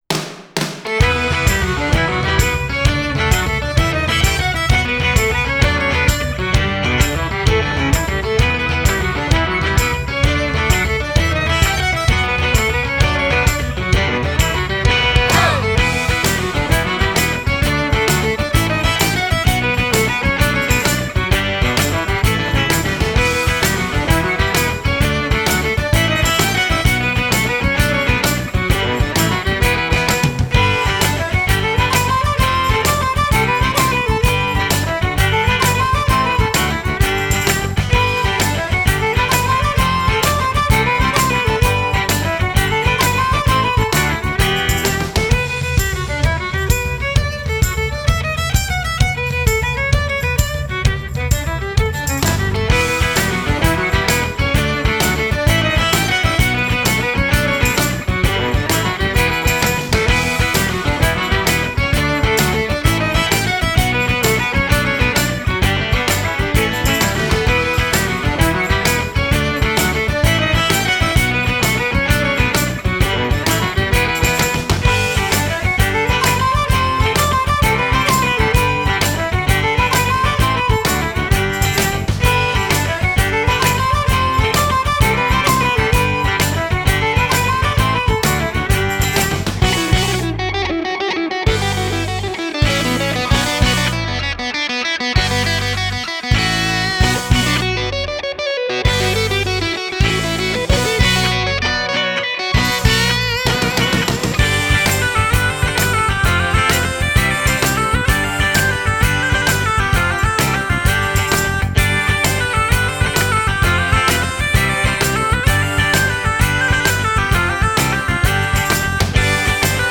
Genre: Folk Rock, Celtic, Medieval